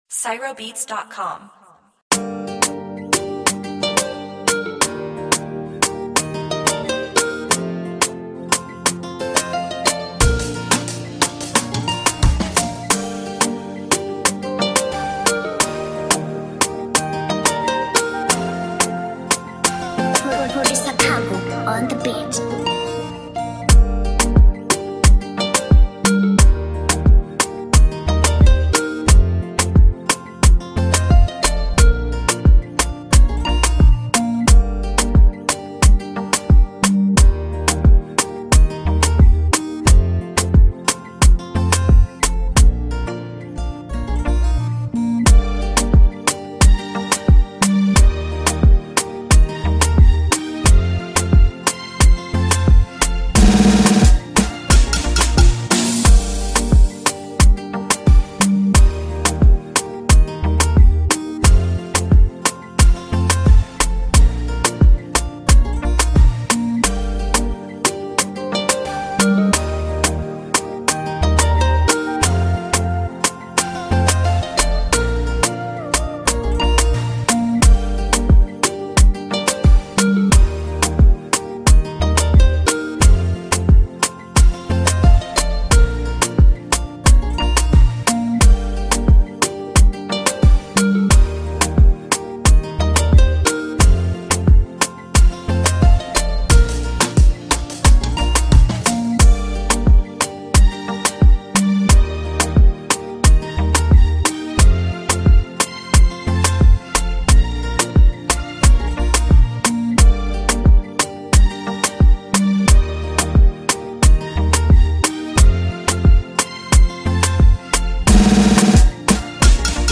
Free Bongo and Zouk Beats Instrumentals Mp3 Download